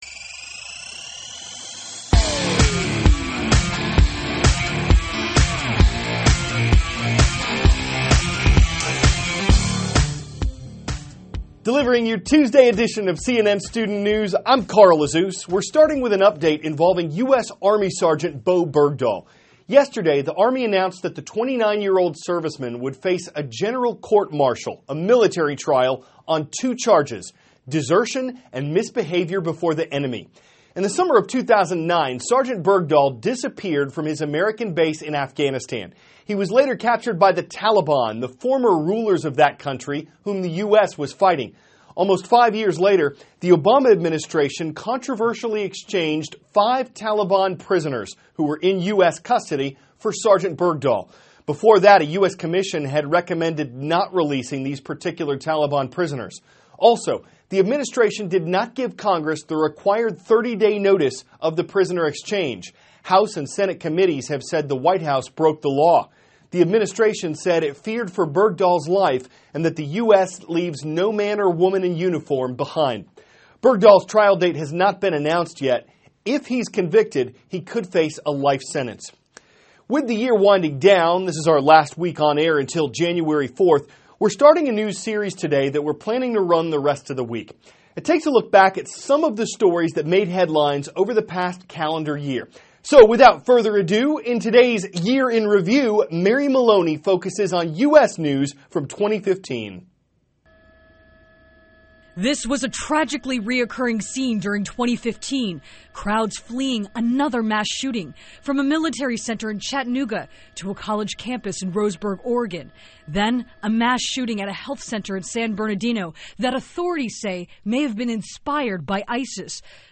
(cnn Student News) -- December 15, 2015 Bowe Bergdahl Faces Court-Martial; 2015 Year in Review; Presidential Debate Preparations THIS IS A RUSH TRANSCRIPT.